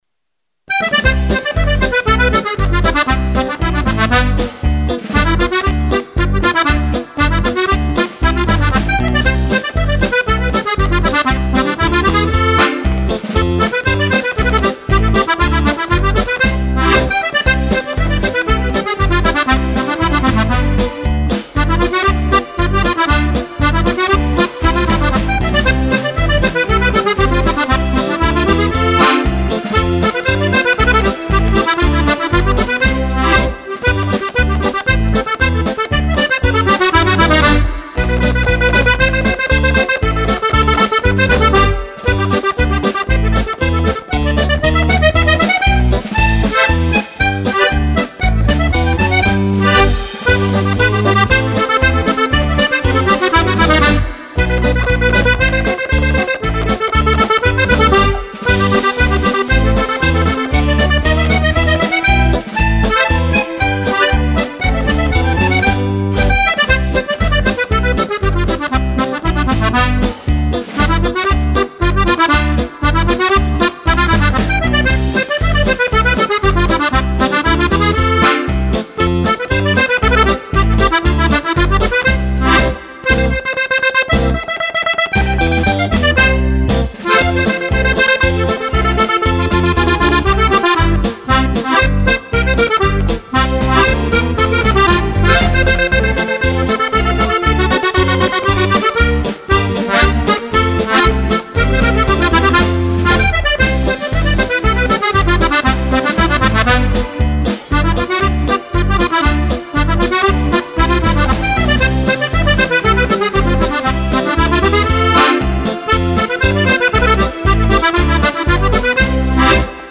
Due ballabili per Fisarmonica